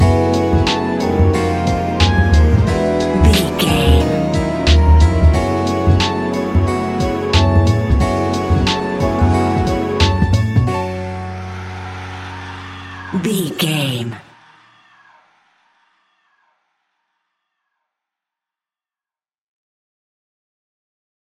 Ionian/Major
F♯
laid back
Lounge
sparse
new age
chilled electronica
ambient
atmospheric